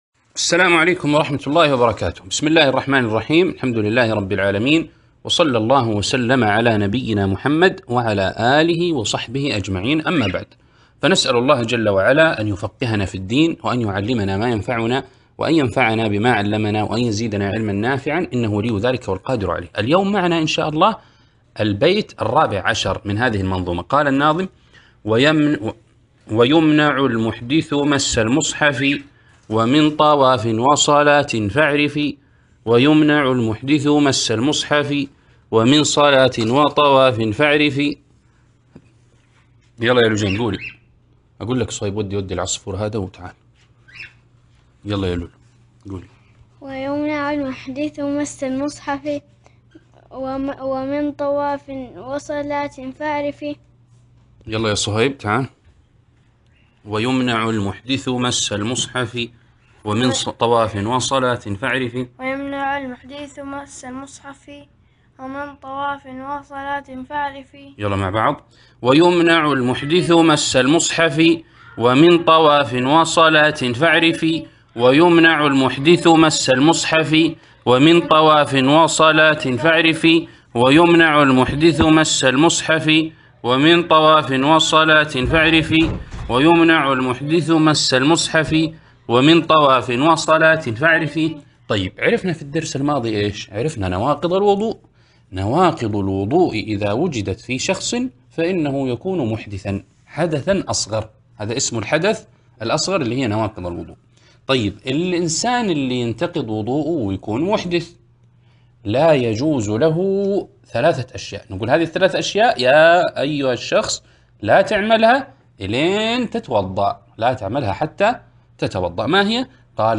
عنوان المادة الدرس ( 14)نواقض الوضوء 3- شرح النظم الأصغر في الفقه للأطفال (الإصدار الأول 1439هـ)